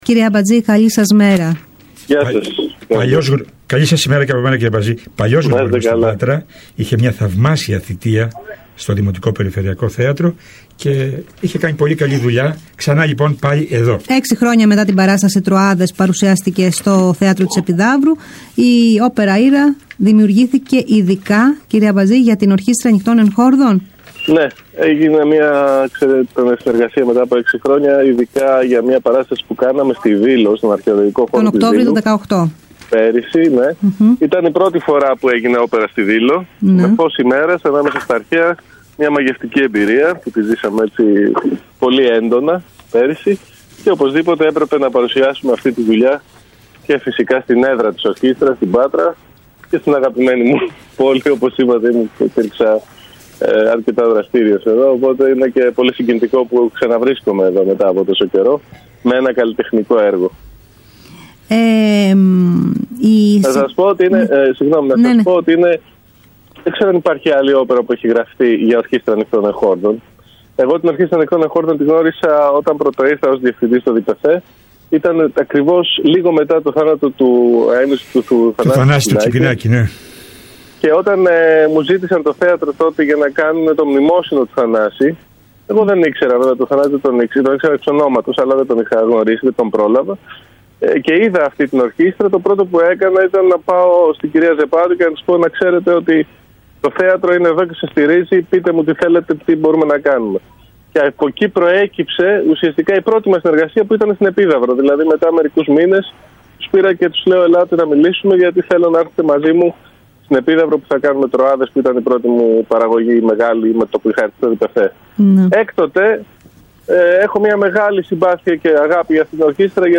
φιλοξενούμενος  στην εκπομπή “Στον αέρα …μέρα παρά μέρα”  συνεργάζεται ξανά με την Ορχήστρα Νυκτών Εγχόρδων “Θανάσης Τσιπινάκης” του Δήμου Πατρέων